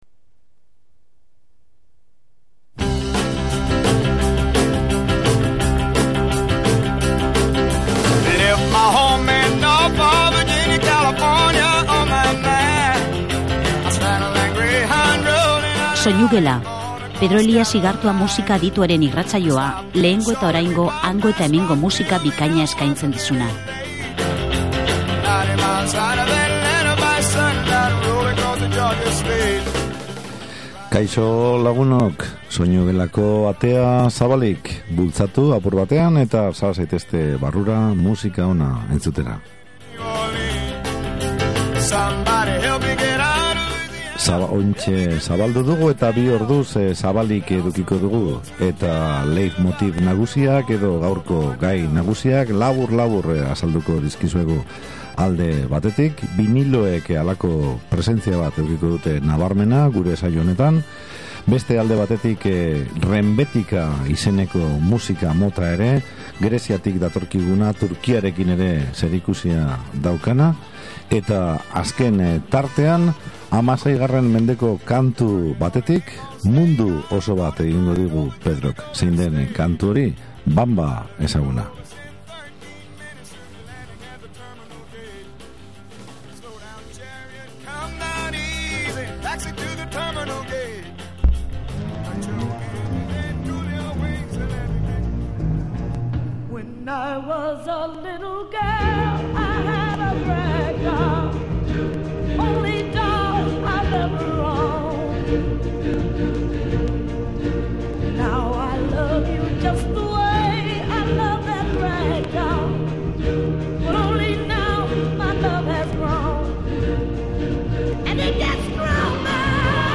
Rock munduko hiru musikari klasikok